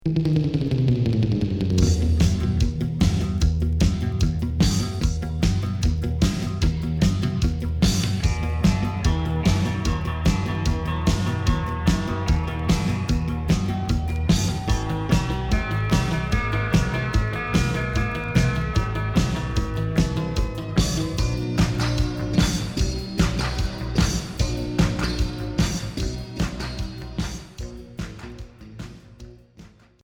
Rock 50's instrumental Deuxième 45t retour à l'accueil